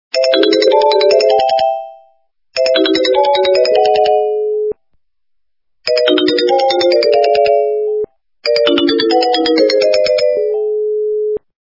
При прослушивании Звонок для СМС - Sony Ericsson качество понижено и присутствуют гудки.
Звук Звонок для СМС - Sony Ericsson